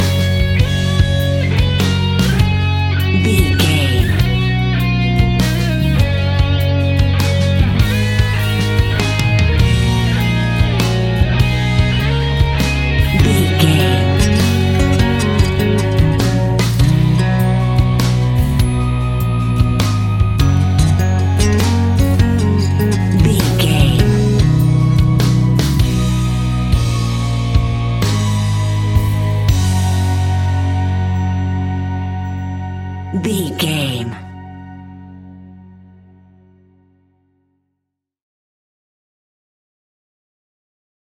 Ionian/Major
indie pop
fun
energetic
uplifting
cheesy
instrumentals
upbeat
groovy
guitars
bass
drums
piano
organ